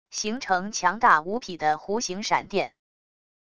形成强大无匹的弧形闪电wav音频